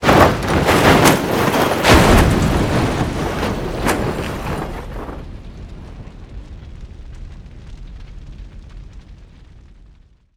vehicleexplode.wav